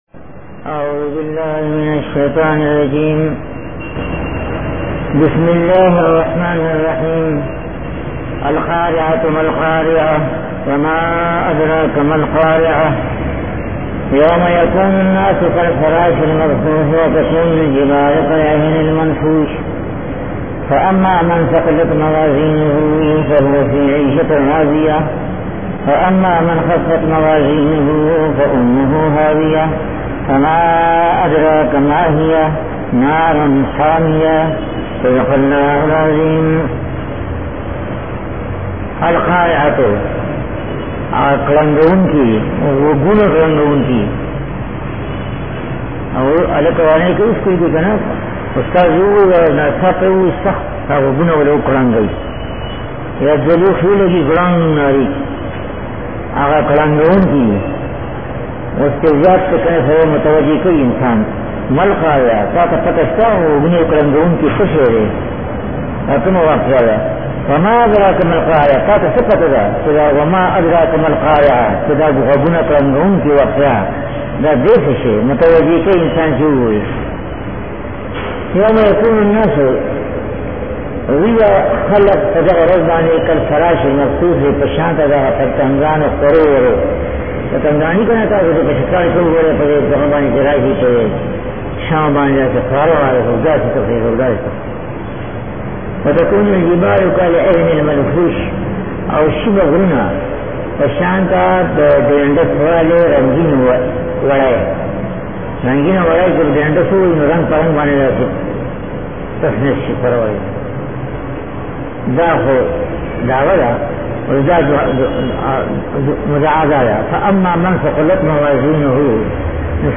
TAFSEER OF THE HOLY QURAN